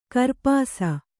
♪ karpāsa